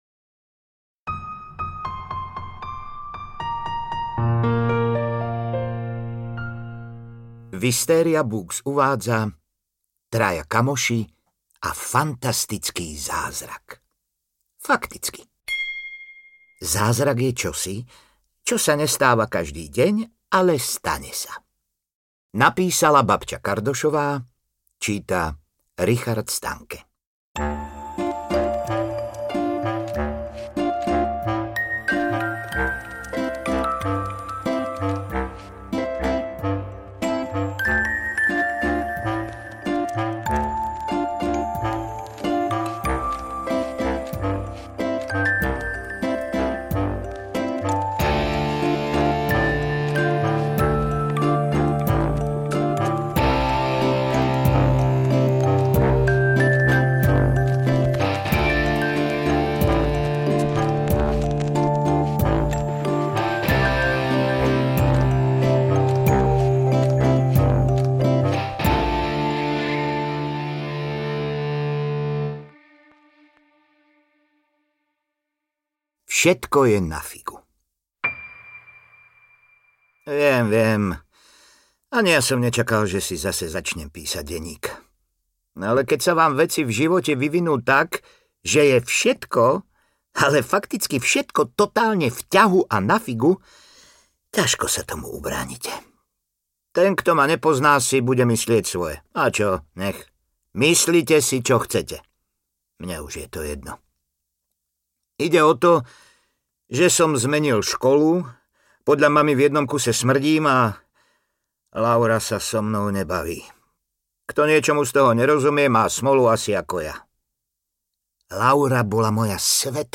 Traja kamoši a fantastický zázrak audiokniha
Ukázka z knihy
• InterpretRichard Stanke